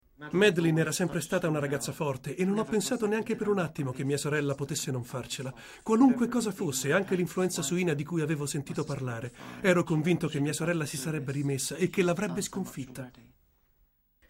Doppiatore Cinema E Televisione per le più grandi società di doppiaggio Italiane.
Sprechprobe: eLearning (Muttersprache):